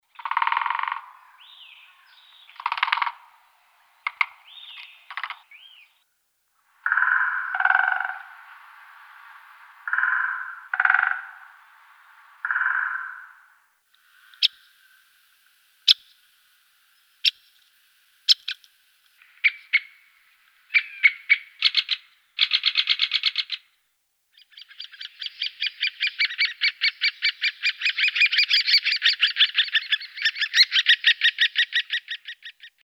Orto botanico - Picchio rosso maggiore
Infatti il picchio usa il becco come scalpello per scavare negli alberi in cerca di insetti o per costruire il nido, nonché per tamburellare segnalando la sua presenza e il possesso del territorio. Entrambi i sessi tamburellano, ma il maschio lo fa più di frequente, soprattutto nel periodo riproduttivo.
picchio_rosso_maggiore.mp3